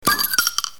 Kategori Lydeffekt